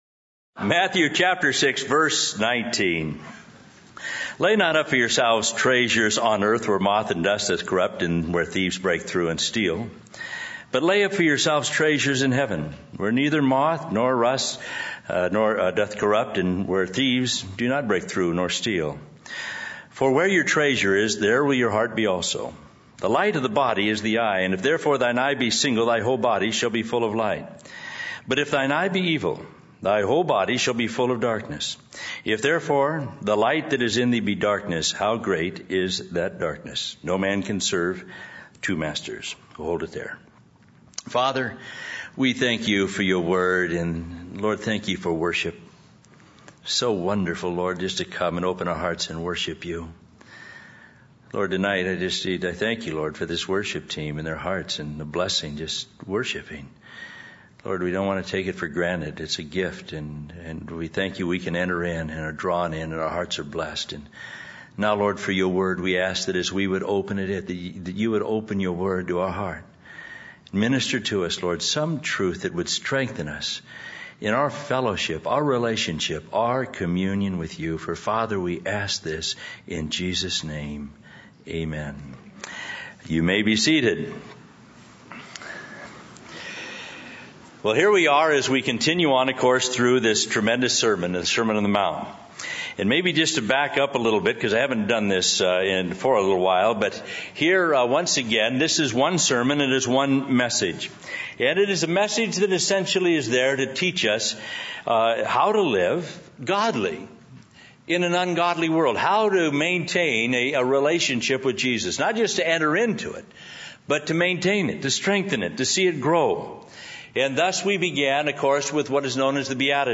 In this sermon, the preacher focuses on Matthew chapter 6 verse 19, which advises against storing up treasures on earth. He emphasizes that earthly treasures are temporary and vulnerable to corruption and theft. Instead, he encourages the congregation to prioritize storing up treasures in heaven, where they are safe from decay and theft.